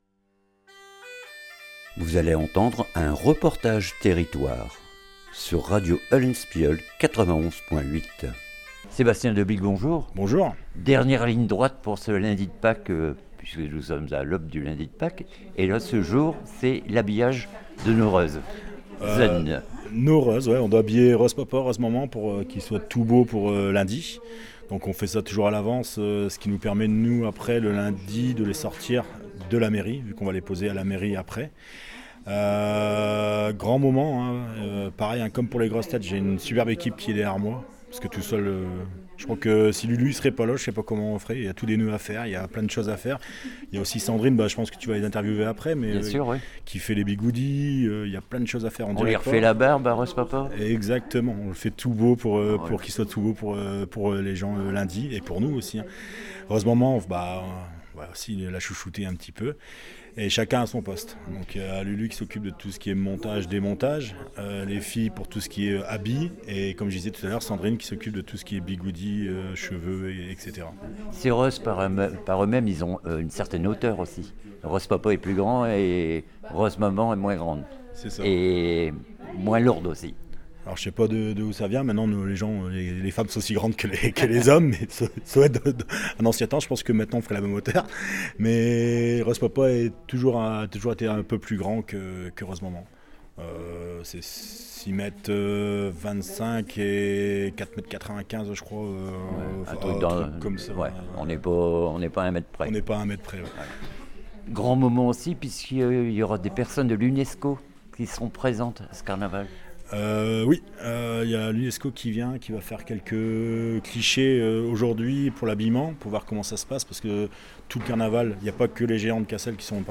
REPORTAGE TERRITOIRE REUZEN VAN CASSEL
A CASSEL !